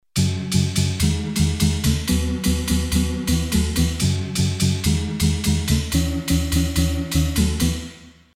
percussion metal
percussion.mp3